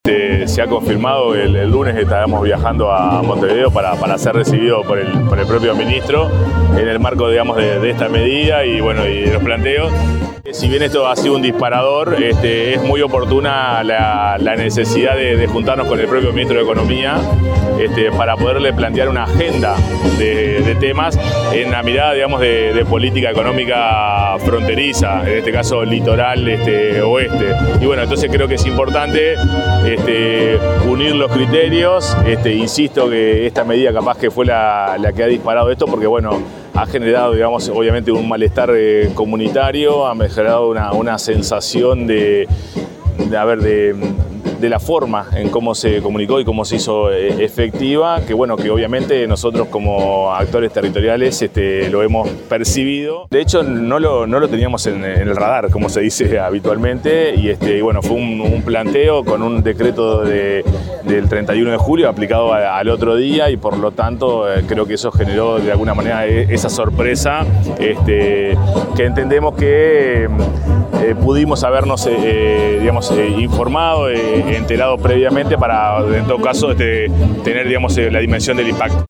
Informe
GUILLERMO-LEVRATTO-Reunion-intendentes-del-litoral-con-Oddone.mp3